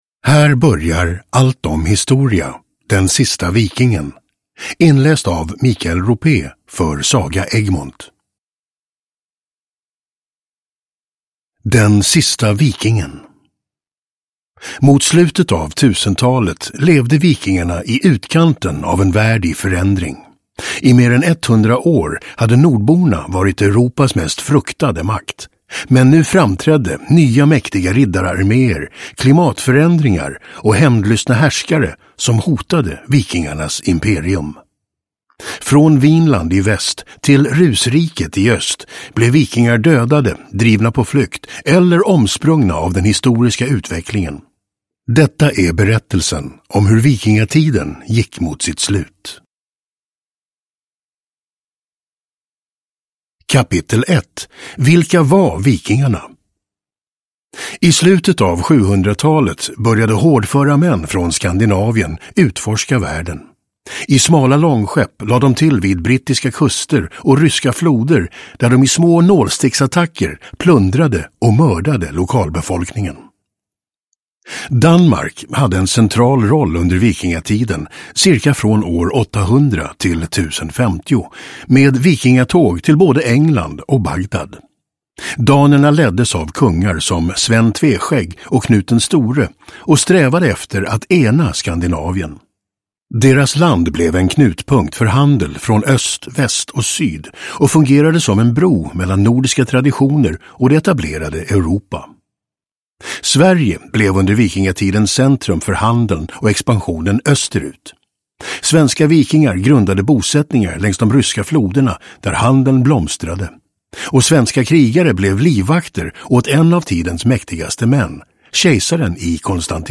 Den sista vikingen (ljudbok) av Allt om Historia